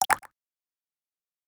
openMenu.ogg